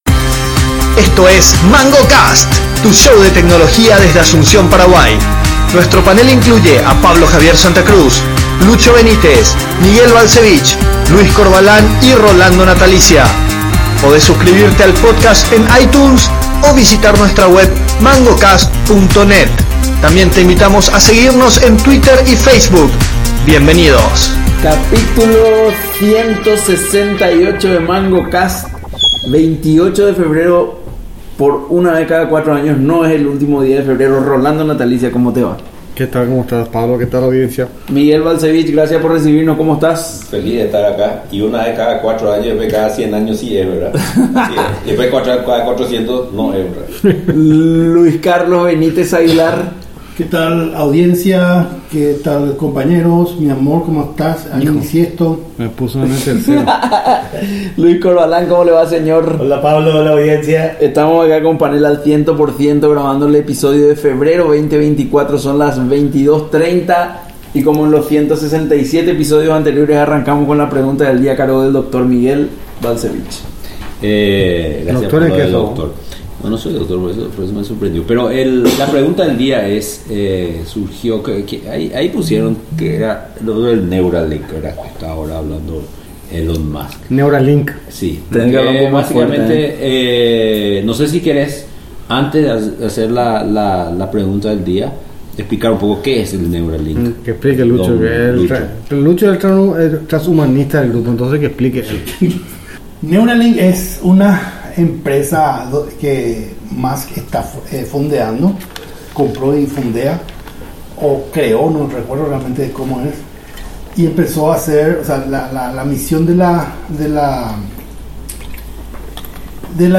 El panel de expertos explora diversas perspectivas sobre el tema, desde la ética y los riesgos potenciales hasta las posibles aplicaciones en áreas como la salud, la educación y el deporte.